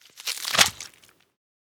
Flesh Peel Sound
horror